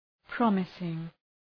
promising.mp3